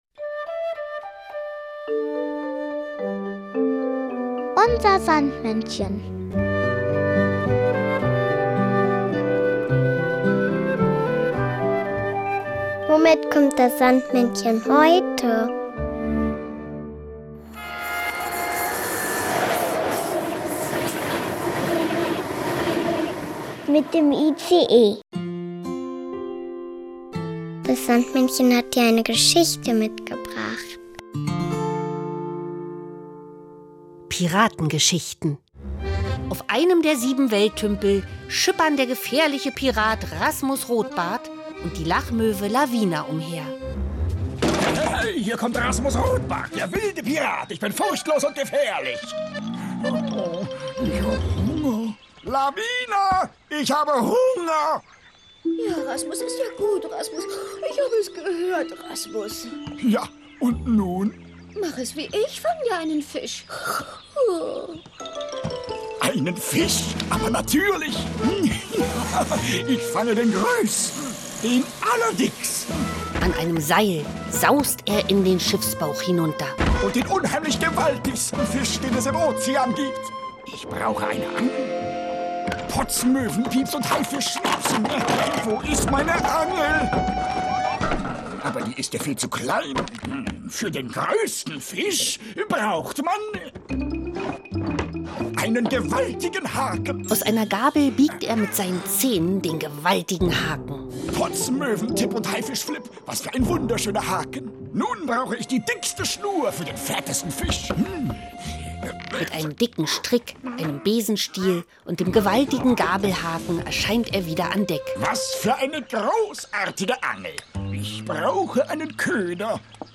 Kinderlied “Im Ozean ist Wochenmarkt” von Barnim Schulz-Kroenert.